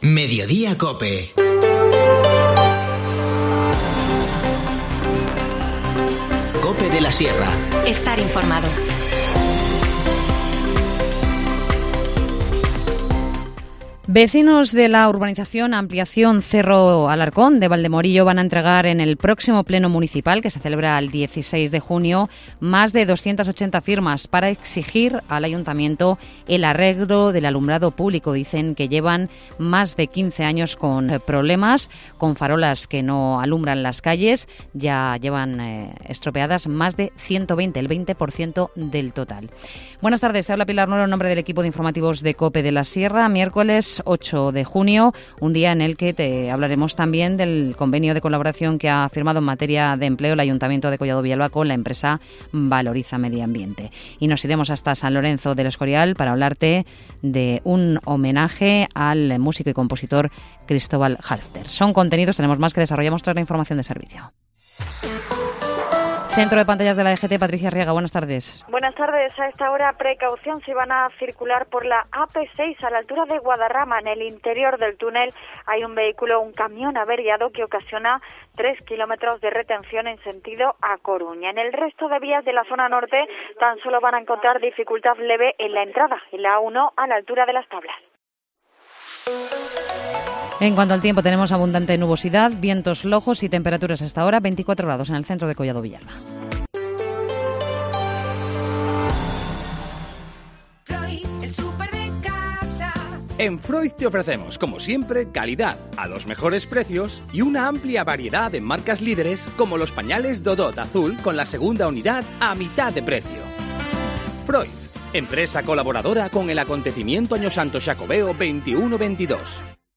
Informativo Mediodía 8 junio